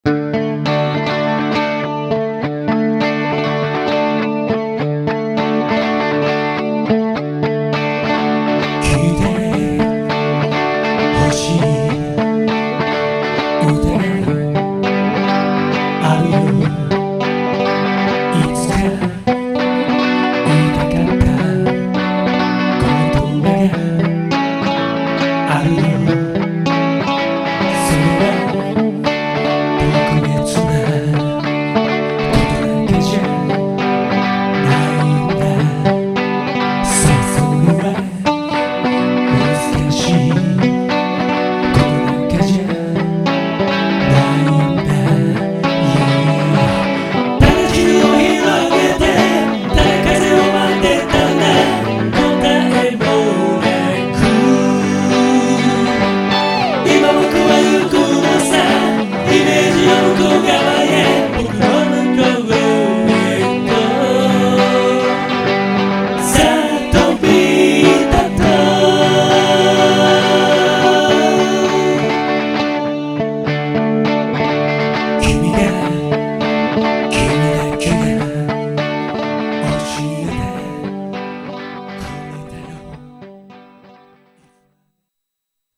過去に、ほんの少し（１分くらい）カバーした曲です。
カッチリコピーではありませんので、軽い気持ちで聴いてみてください。